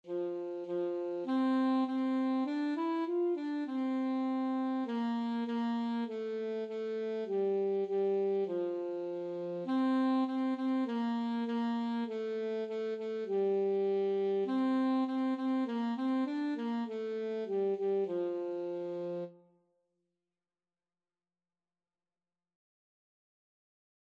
Free Sheet music for Alto Saxophone
4/4 (View more 4/4 Music)
F4-F5
Moderato
Saxophone  (View more Beginners Saxophone Music)